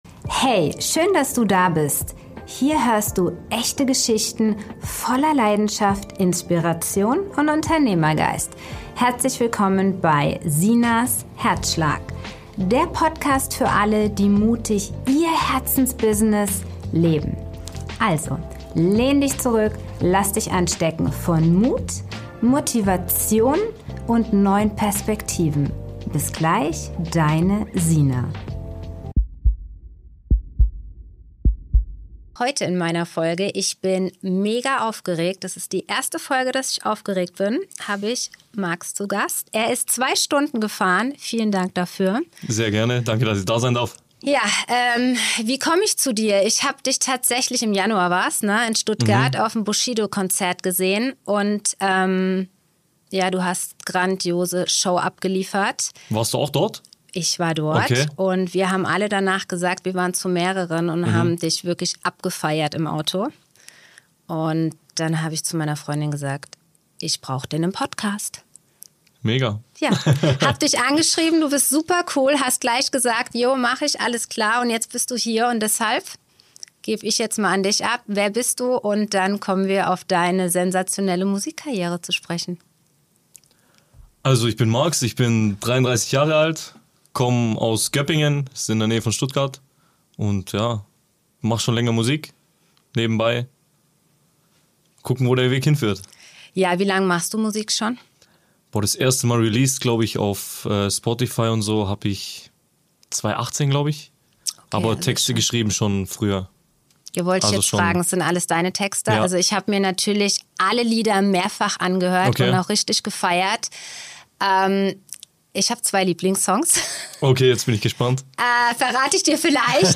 Ich freue mich sehr, dass du den Weg ins Podcast-Studio auf dich genommen hast.